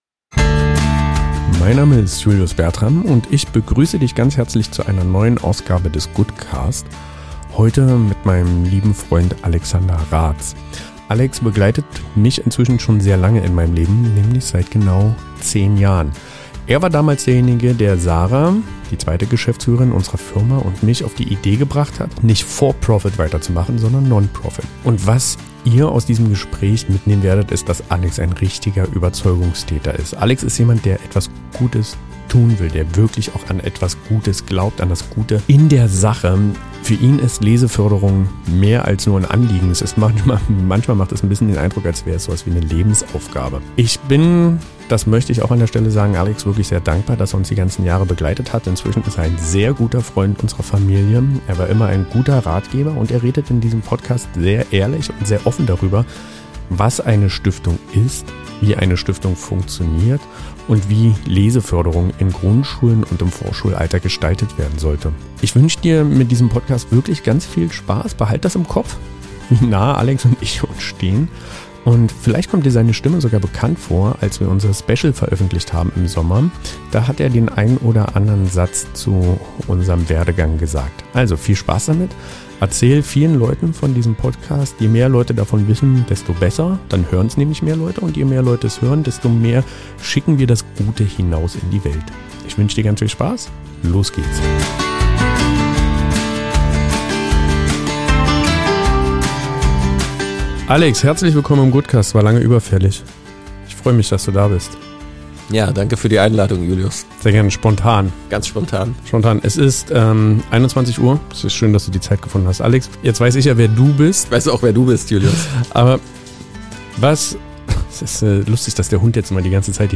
Ein warmes, ehrliches Gespräch – über Chancen, Freundschaft und die Freude daran, etwas weiterzugeben.